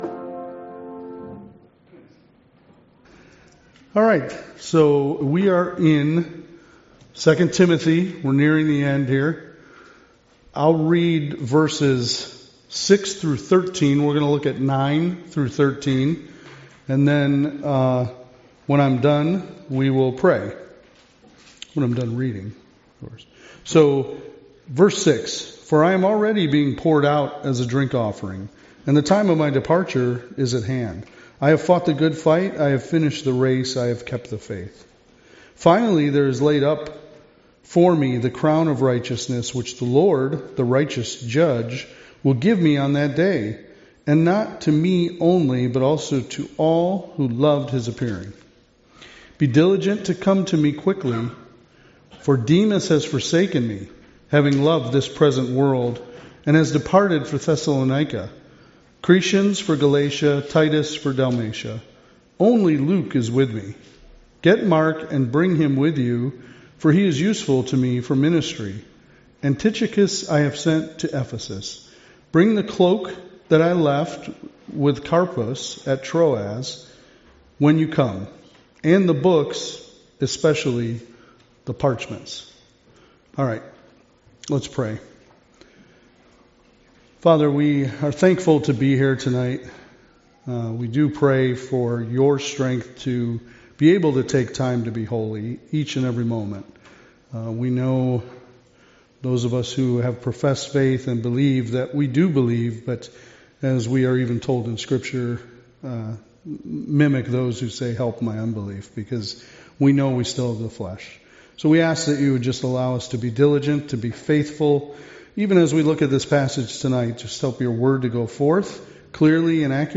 2 Timothy 4 Service Type: Wednesday Devotional « Sorrento